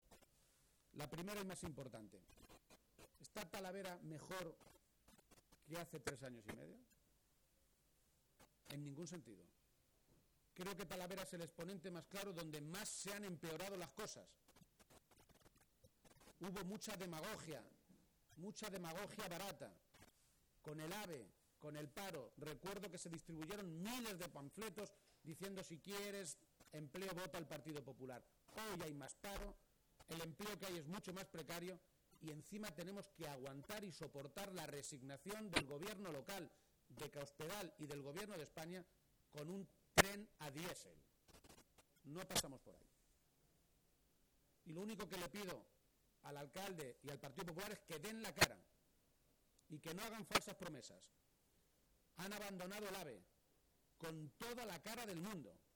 El secretario general del PSOE de Castilla-La Mancha, Emiliano García-Page, ha visitado hoy la Feria de Talavera y allí, a 24 horas de hacer oficial su candidatura a las primarias para ser quien compita por la Presidencia de Castilla-La Mancha, ha hecho un contundente anuncio:”Si en Mayo soy el Presidente de Castilla-La Mancha y antes de Agosto no hay encima de la mesa un Plan de Empleo para 60.000 parados, yo dimitiré”.